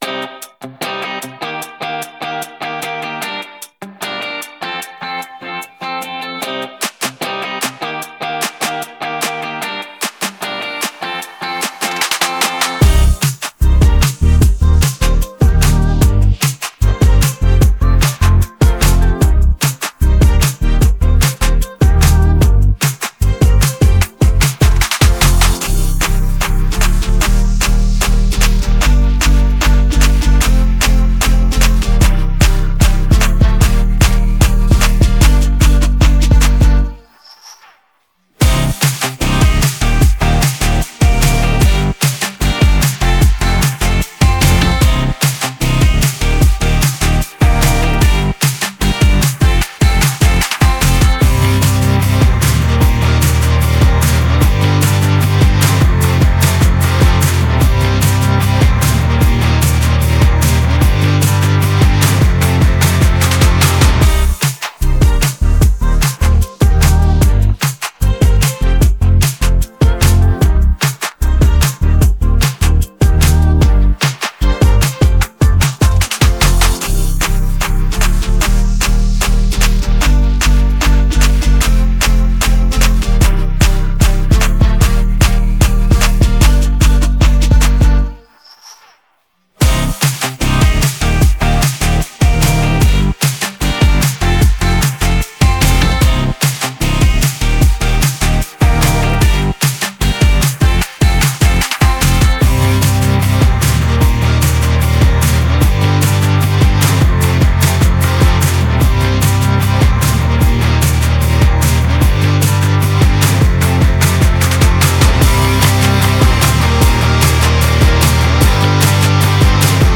• Категория: Детские песни
Слушать минус